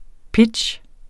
Udtale [ ˈpidɕ ]